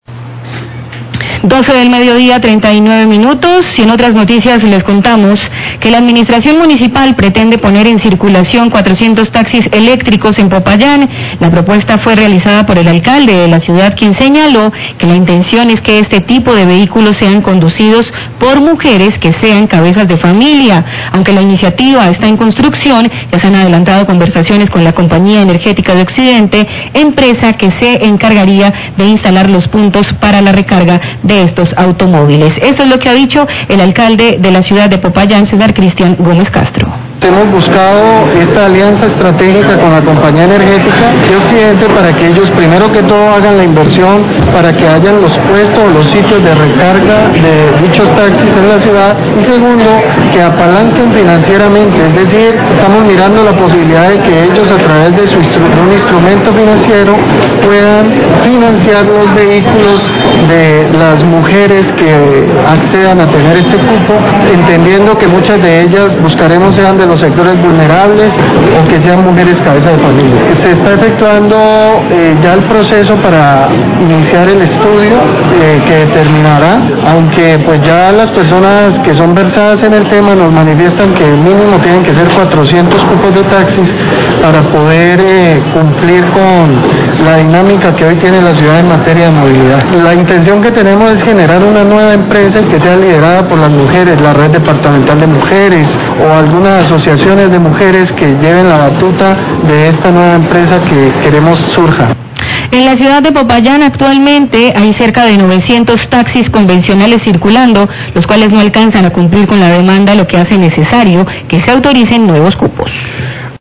Radio
Declaraciones del Alcalde de Popayán, Cesar Cristian Gómez.